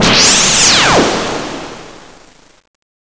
se_gun00.wav